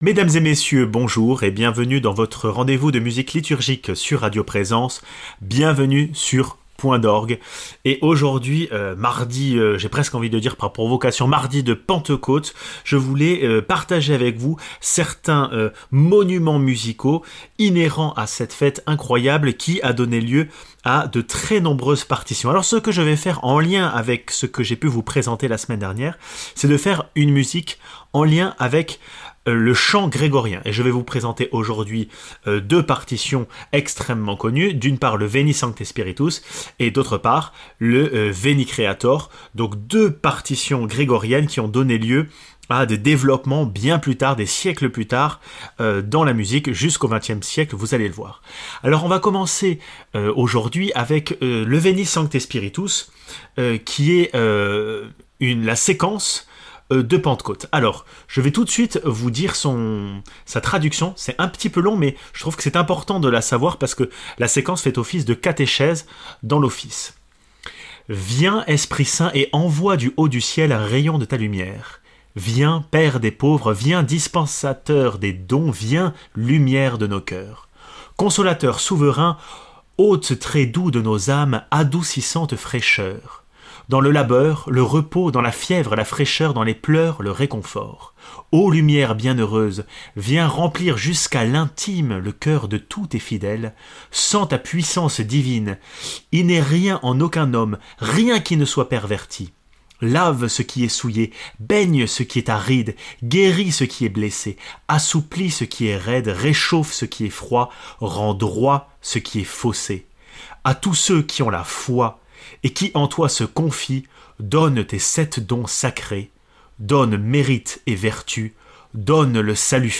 Le grégorien avec le Veni Creator spiritus et le veni sancte spiritus, possède deux pages inspirantes dont se sont emparés les plus grands musiciens.